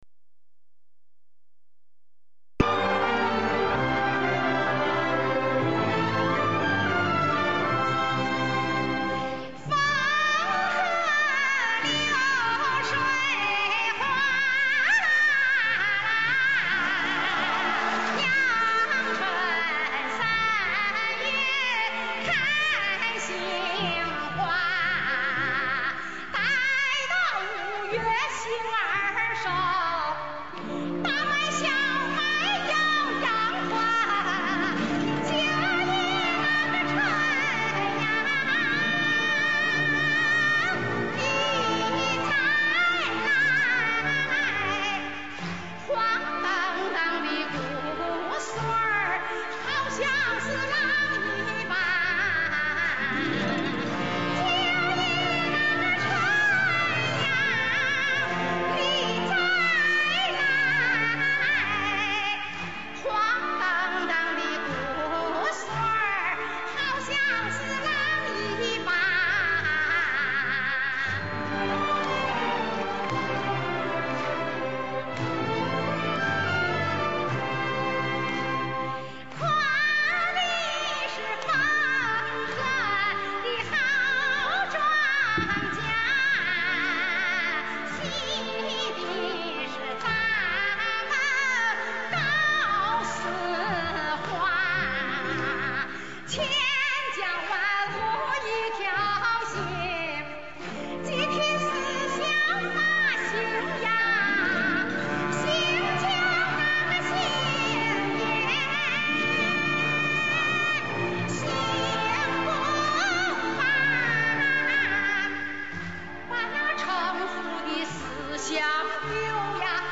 她的表演高亢 富有山西地方乡土气息